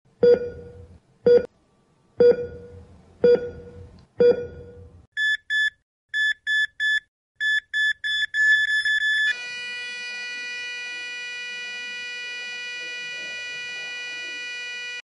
But it would be the most beautiful monotone sound ever in history. Some say it’s the best monotone sound.